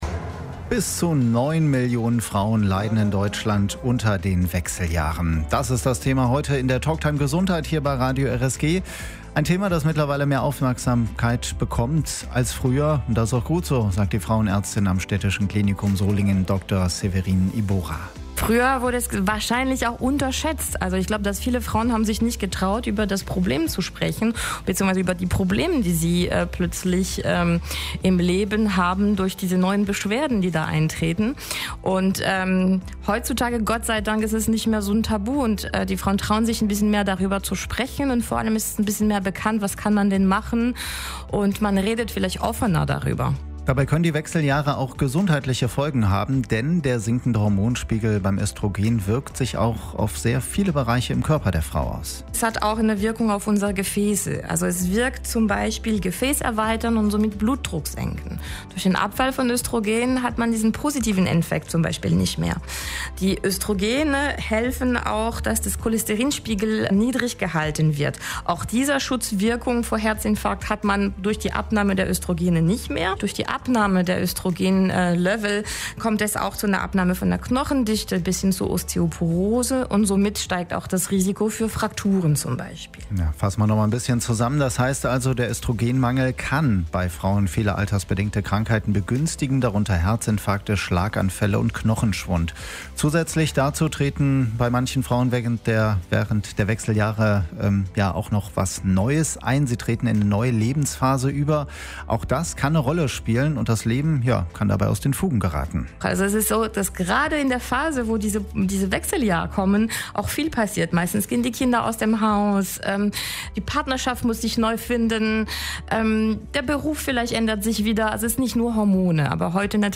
Die Talktime Gesundheit lief am Samstag, 21. Juni, von 12 bis 13 Uhr bei Radio RSG - wenn ihr etwas runterscrollt, könnt die Sendung hier noch einmal hören.